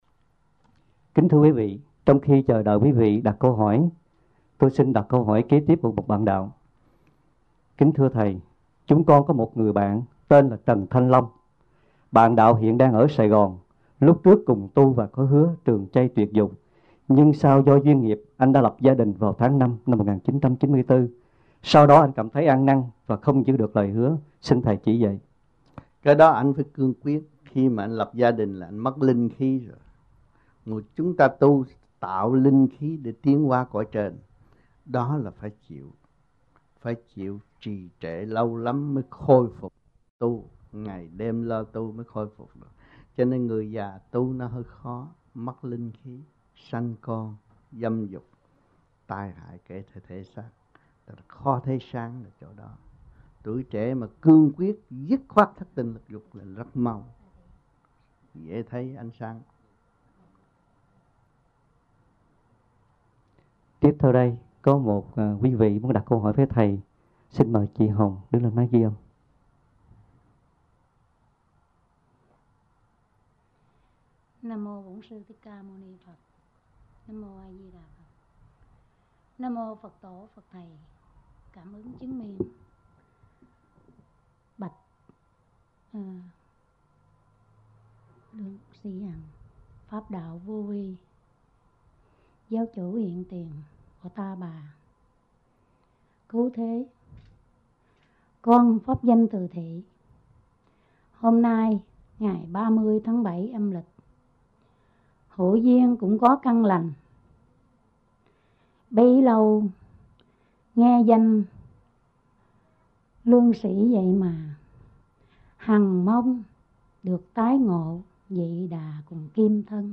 Westminster, California, United States Trong dịp : Sinh hoạt thiền đường >> wide display >> Downloads